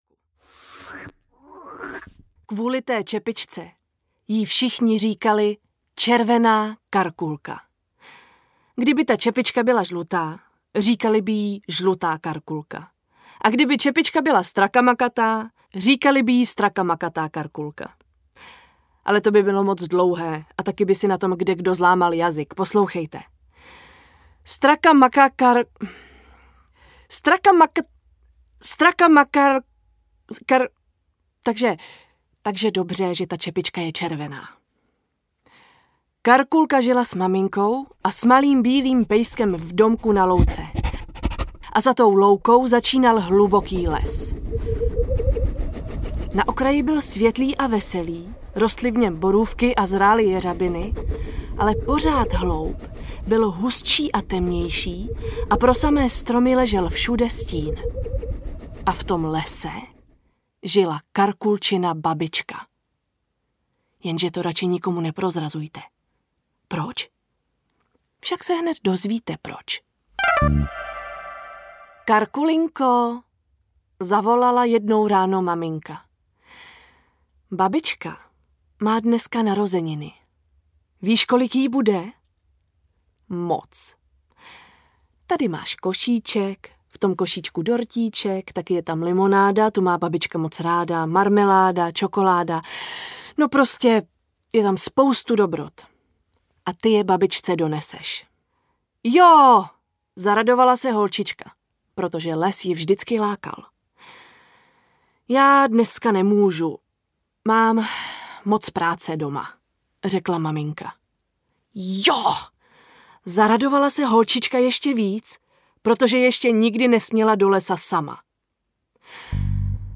Klasické pohádky audiokniha
Ukázka z knihy
• InterpretBarbora Hrzánová, Taťjana Medvecká, Denisa Barešová, David Novotný, Vladimír Javorský
klasicke-pohadky-audiokniha